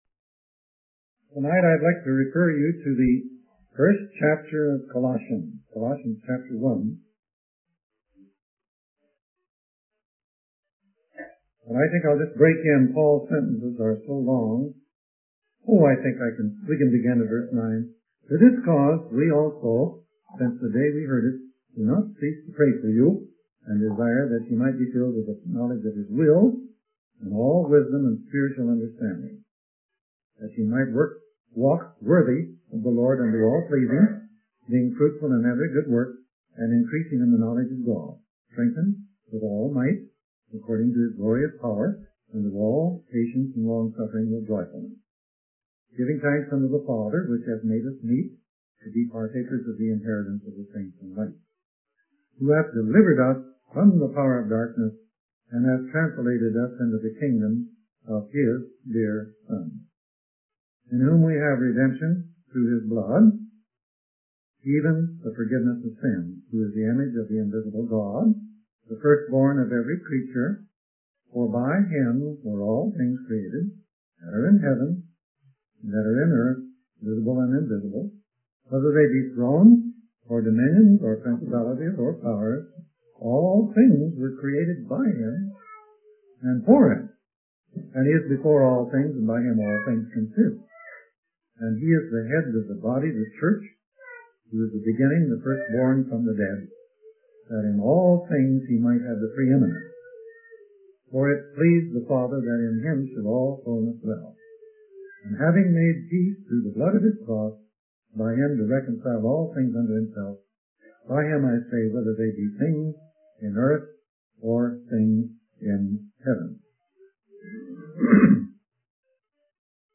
In this sermon, the preacher discusses the concept of atoms and how they revolve around a center, creating centrifugal force.